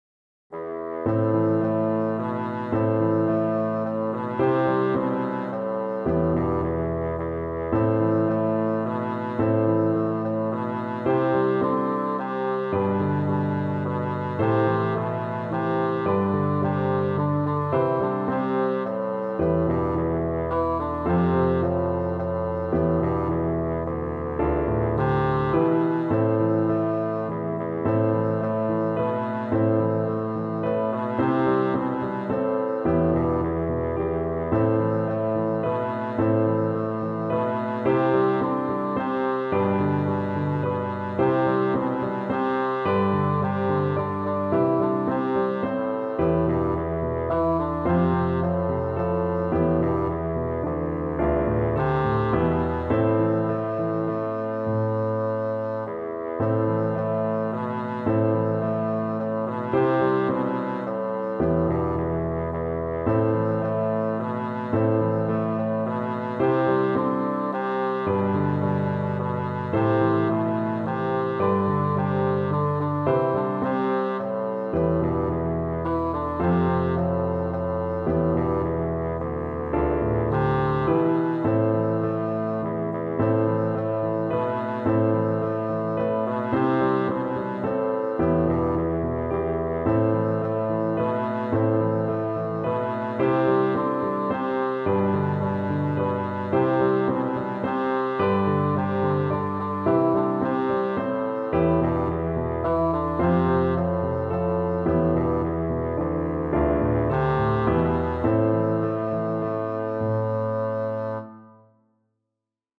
a Traditional Nautical Folk Song